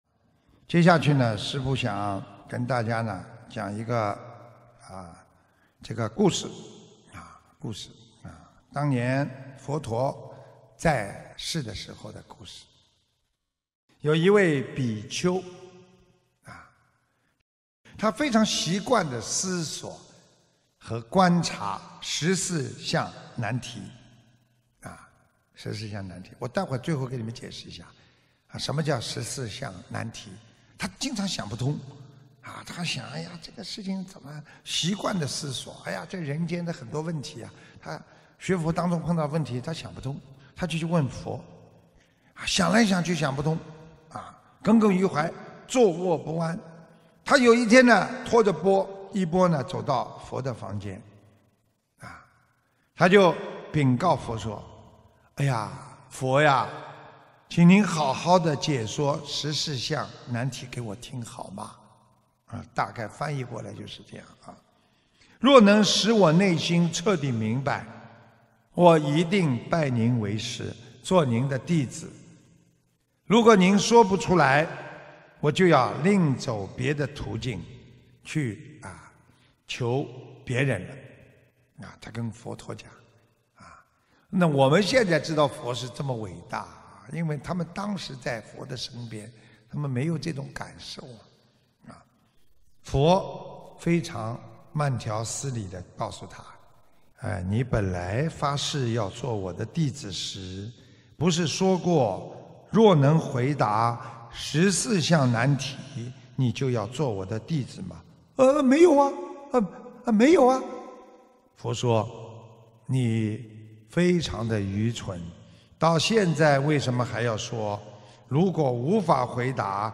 视频：100.《执着佛理，解脱无益》白话佛法视频开示，师父讲故事！